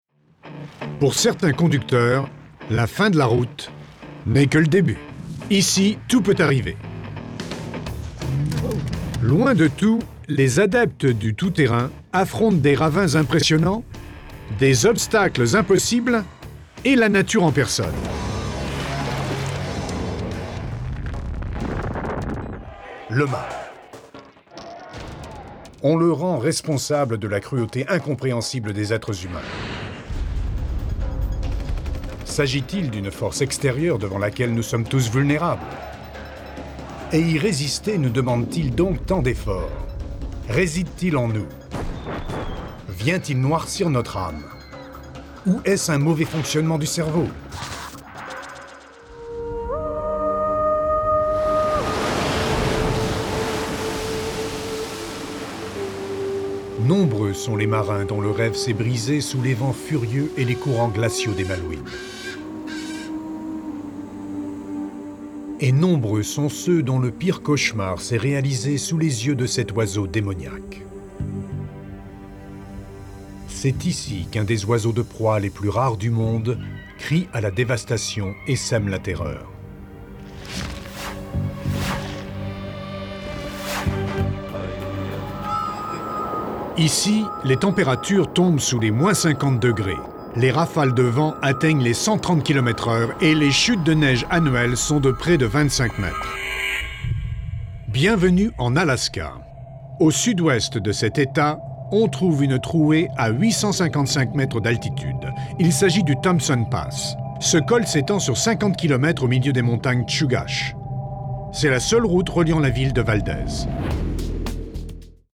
French Native (US Citizen)
Ultimate Journey                  Documentary Narrator          Discovery Channel (France)